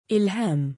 母音記号あり：إِلْهَام [ ’ilhām ] [ イルハーム ]